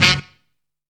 NOW BASS.wav